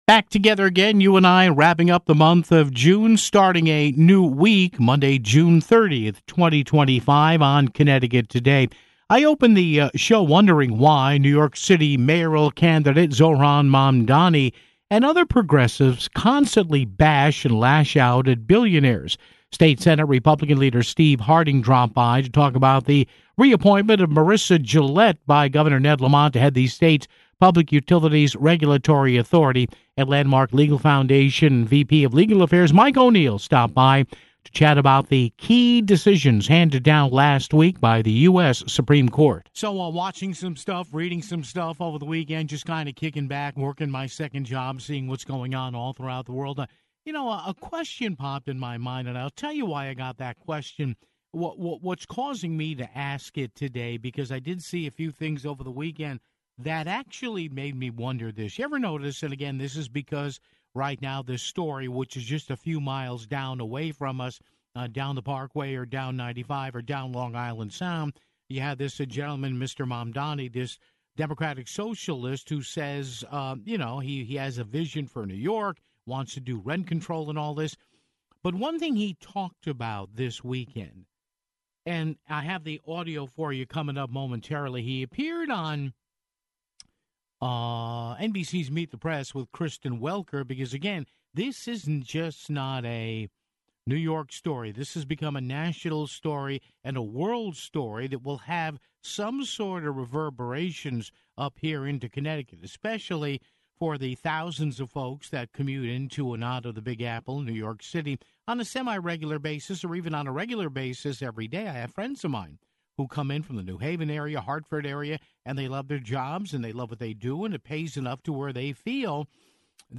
State Senate GOP Leader Steve Harding talked about the re-appointment of Marissa Gillett by Governor Ned Lamont to head the state's Public Utilities Regulatory Authority (15:05).